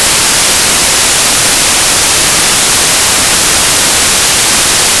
NOISE WAV FILES MADE WITH PYTHON
This program makes random noise.
Click here for an example of a WAV file, with noise
13justnoise.wav